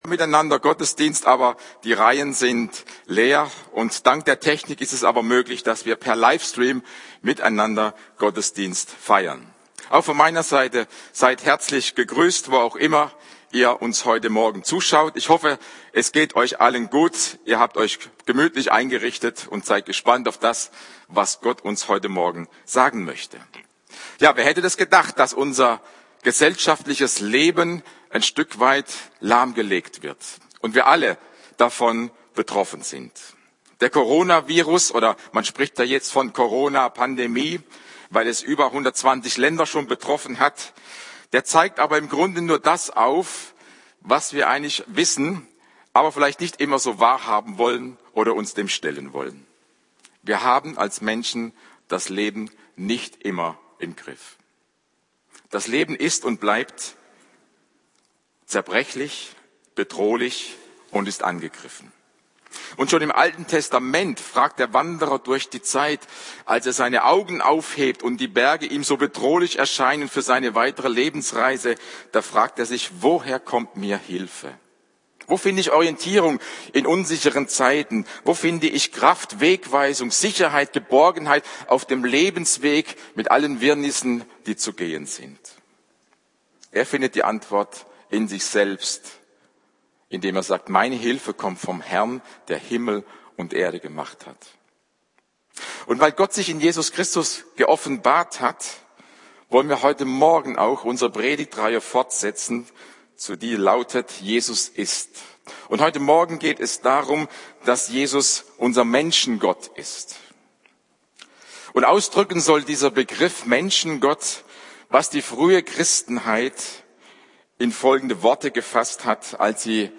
Die Predigt wurde per Livestream übertragen.